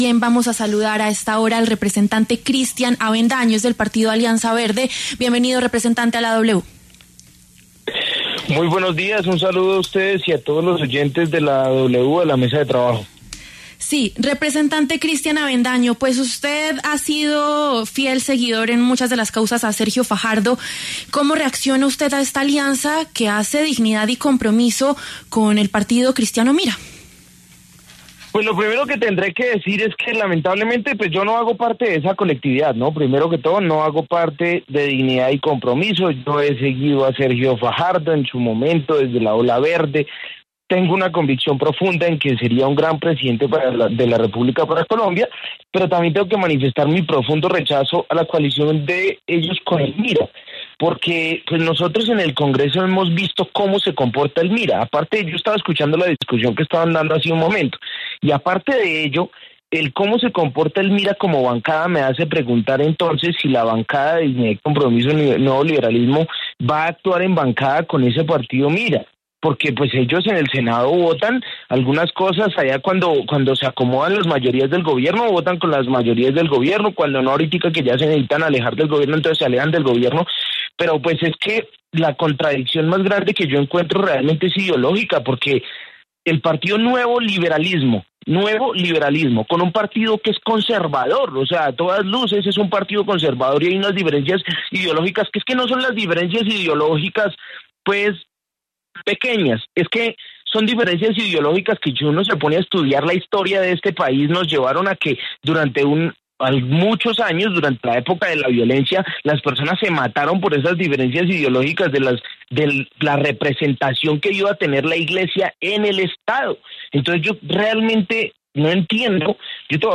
En entrevista con La W, el representante de la Alianza Verde y cercano a Sergio Fajardo, Cristian Avendaño, cuestionó duramente la nueva coalición que se anunció entre los partidos de centro Dignidad y Compromiso y el Nuevo Liberalismo, con el partido cristiano MIRA.